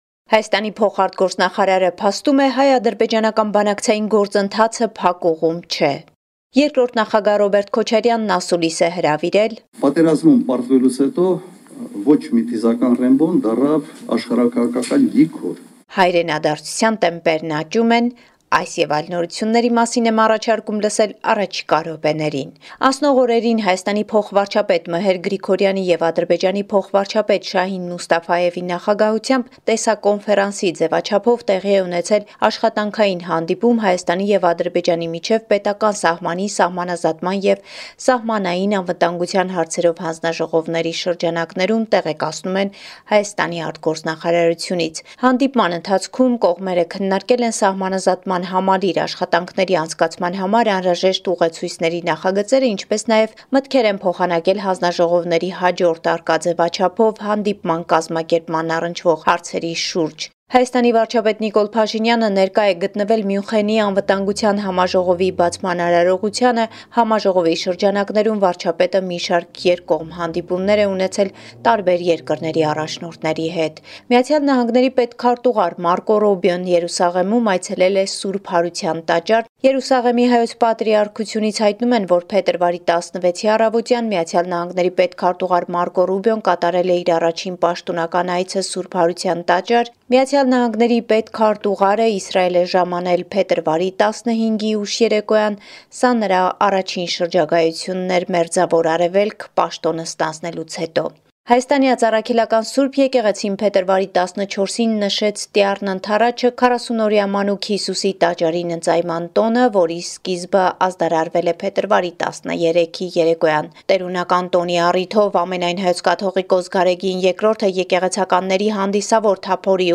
Latest news from Armenia, Artsakh and the Diaspora